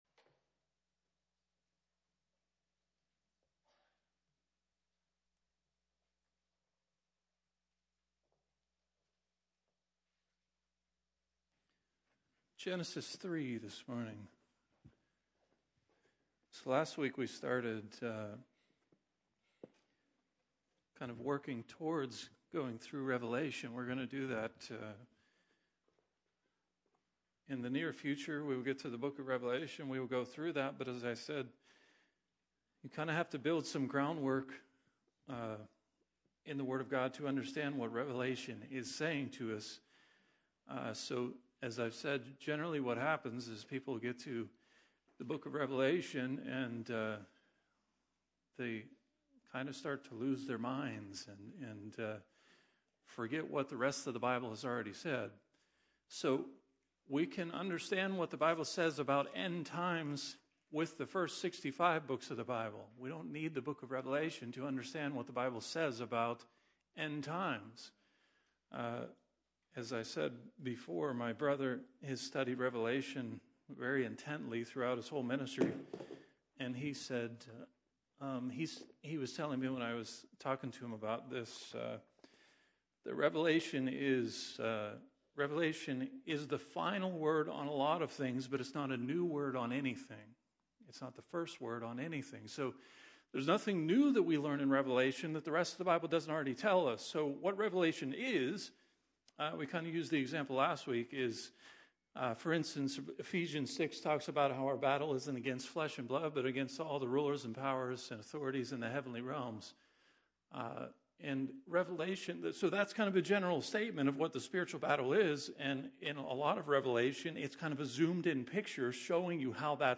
Sermons | Barrs Mill Church of God